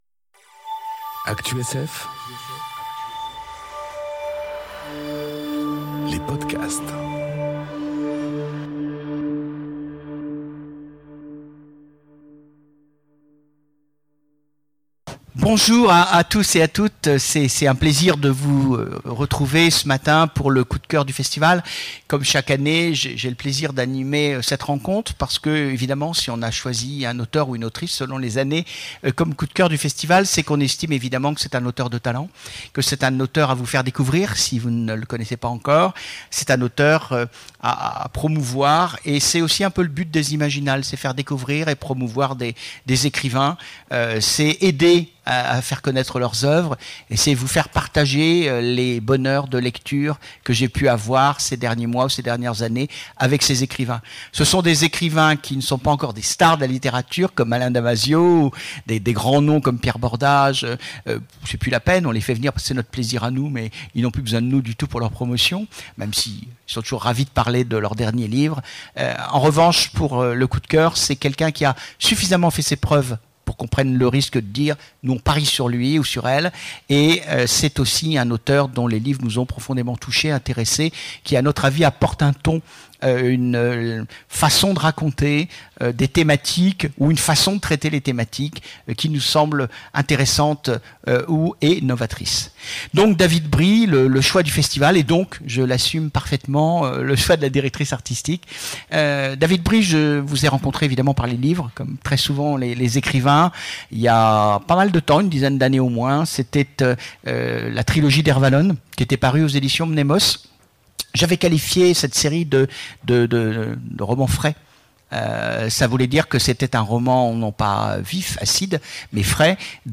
Imaginales 2019